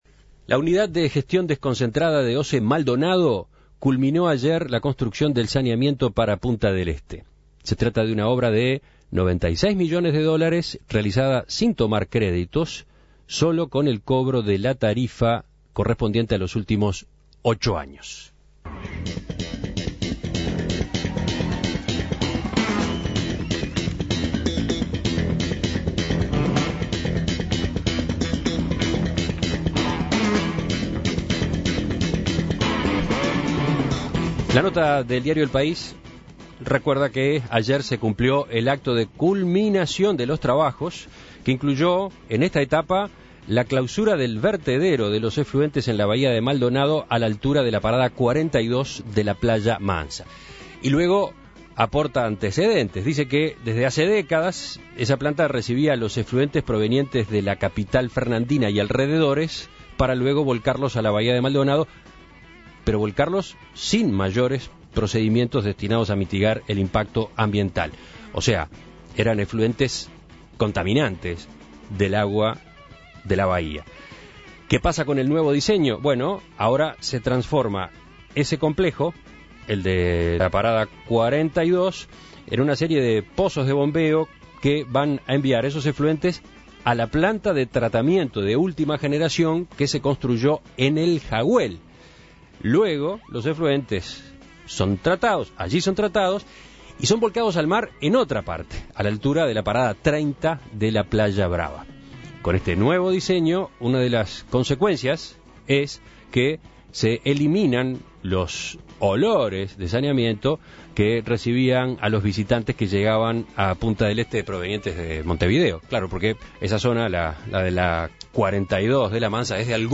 El nuevo diseño transformó el proceso enviando los efluentes a una planta con tecnología de punta ubicada en El Jaguel. En diálogo con En Perspectiva